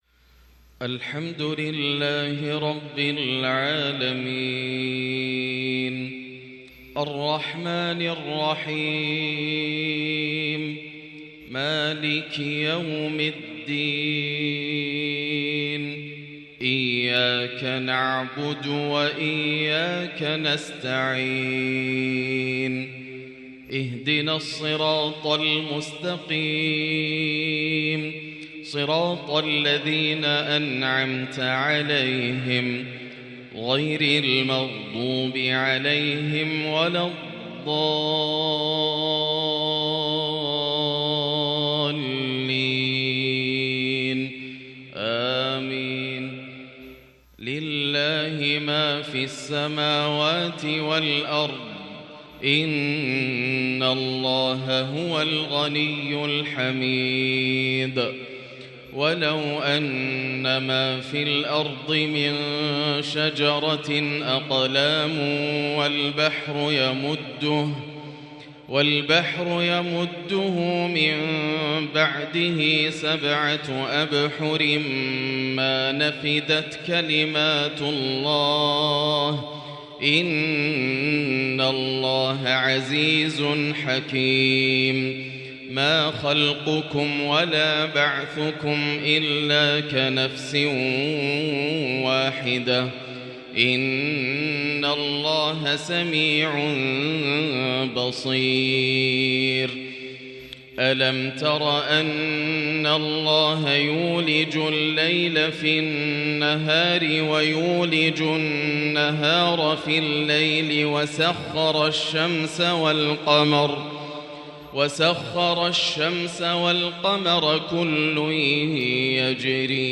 “وأيوب إذ نادى ربه” أسبوع مذهل من الفجريات الكردية المميزة لغريد الحرم د.ياسر الدوسري > تلاوات عام 1444هـ > مزامير الفرقان > المزيد - تلاوات الحرمين